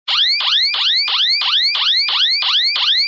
SSiren.ogg